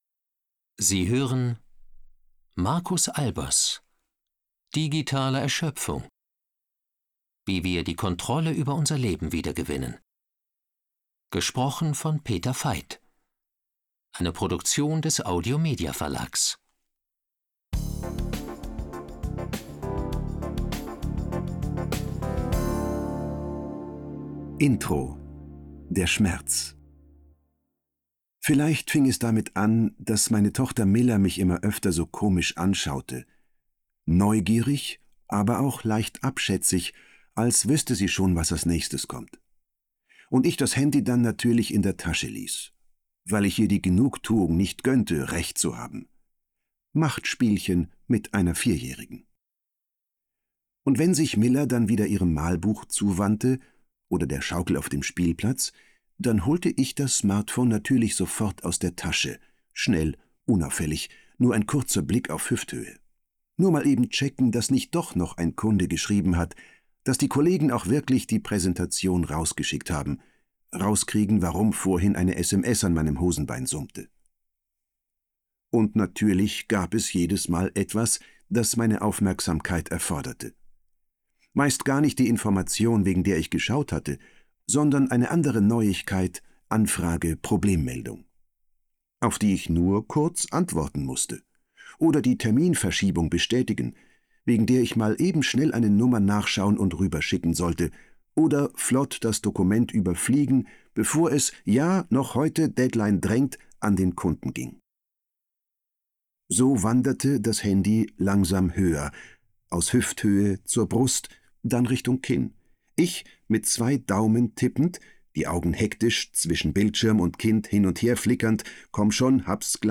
Schlagworte Arbeitswelt • Belastung • Beruf • digitales Arbeiten • Digitales Leben • Digitalisierung • Erreichbarkeit • Erschöpfung • Führungskraft • Hörbuch; Literaturlesung • Lösung • Neues Arbeiten • Problem